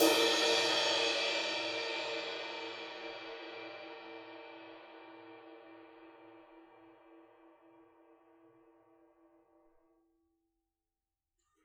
susCymb1-hitstick_fff_rr1.wav